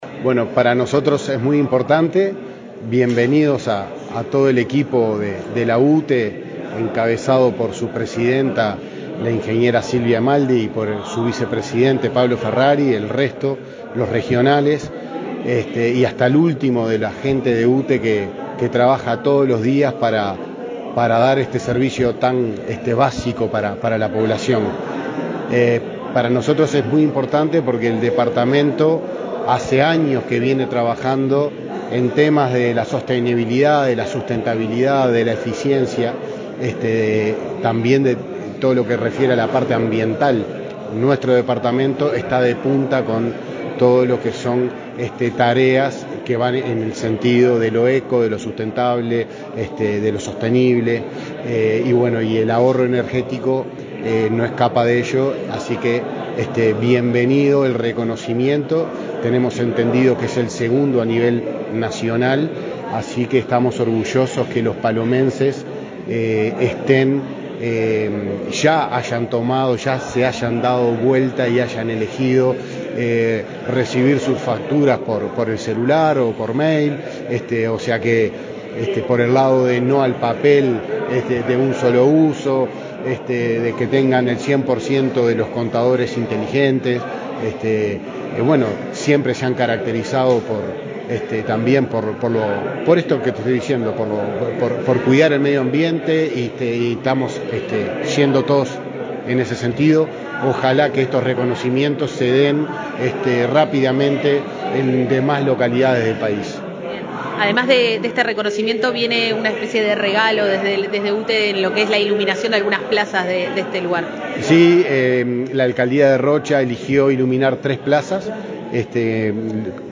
Entrevista al intendente de Rocha, Nicolás García
El intendente de Rocha, Nicolás García, dialogó con Comunicación Presidencial, este viernes 23 en el departamento de Rocha, al recibir el premio Comunidad Inteligente para el balneario La Paloma, en el marco del programa de UTE que reconoce a lugares que han logrado el 100% de medición remota y disponen de cargadores eléctricos para la movilidad sostenible, entre otros indicadores.